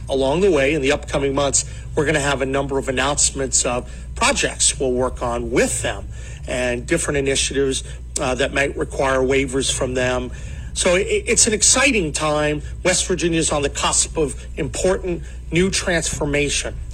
The incoming Governor of West Virginia says plans are already in place to work with the Trump administration.  Patrick Morrisey will be sworn in as governor on January 13 in Charleston but told reporters he has been talking about ways to team up with the incoming president…